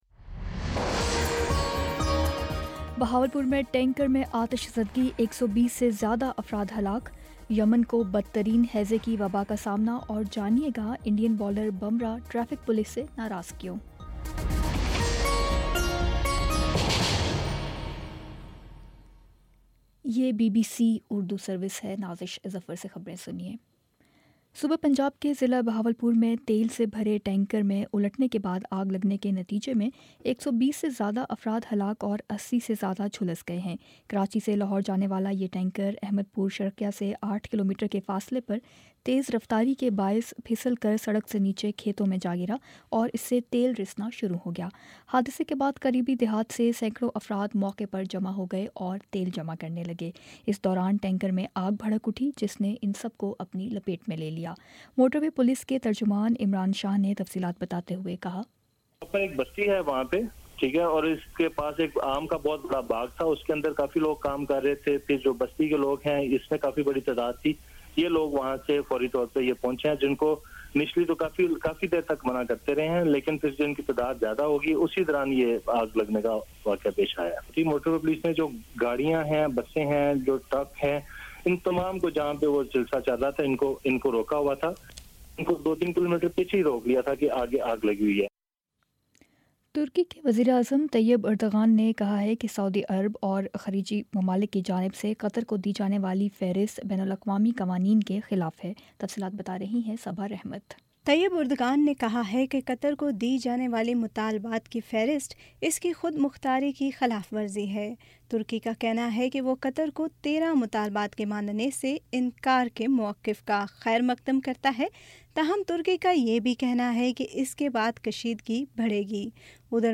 جون 25 : شام پانچ بجے کا نیوز بُلیٹن